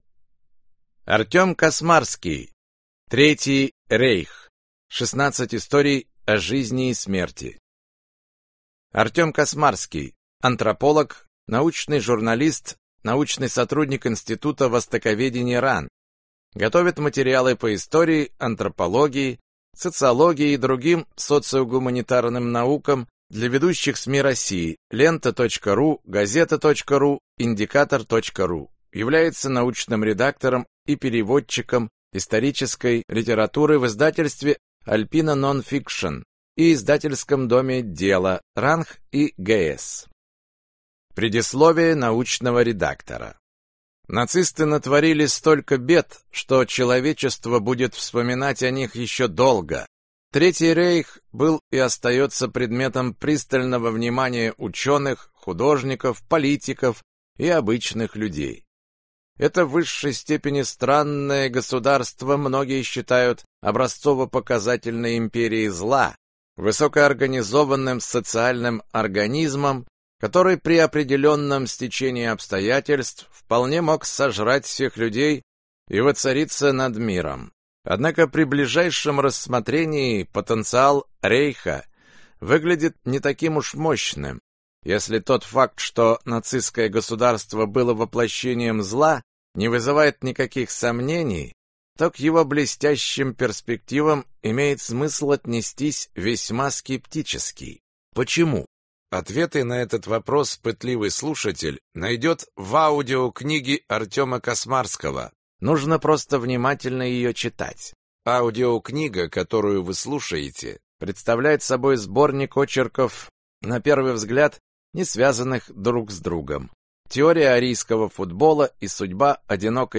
Аудиокнига Третий рейх. 16 историй о жизни и смерти | Библиотека аудиокниг